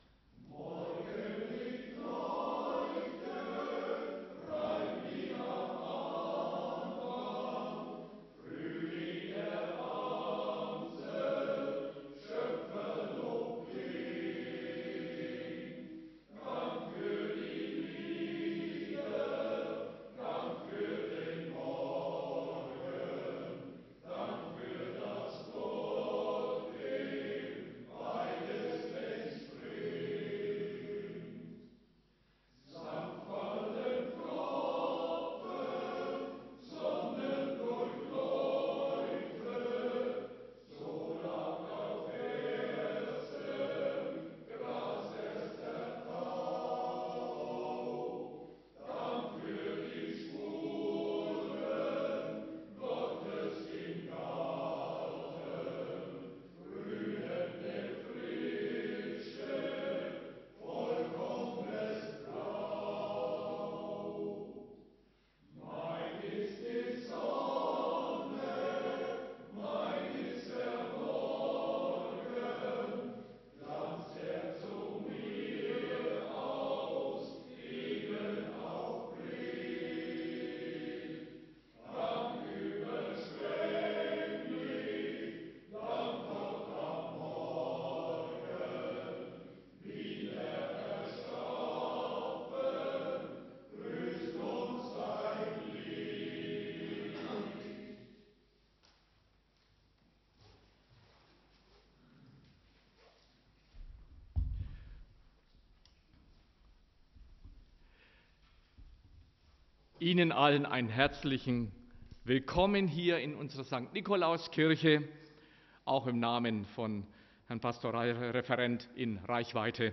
250601_Ökumen.Schöpfungsandacht_k.mp3